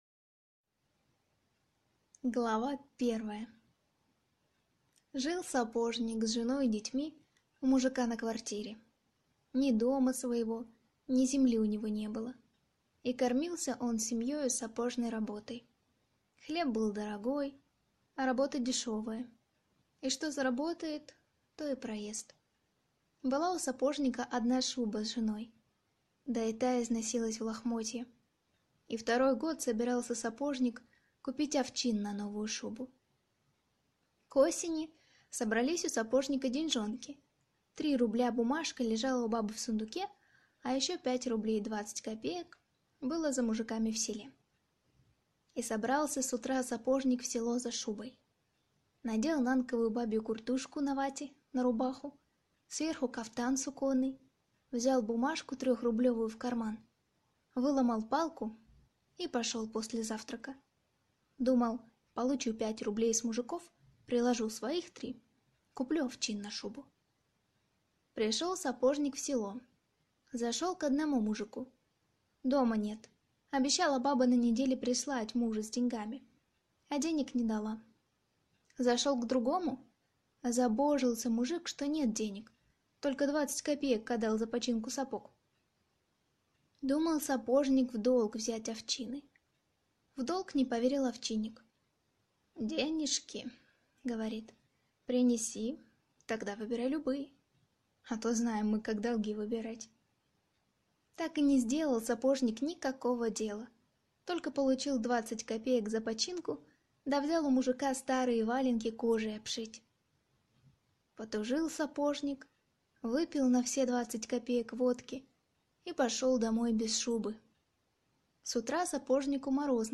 Аудиокнига Чем люди живы | Библиотека аудиокниг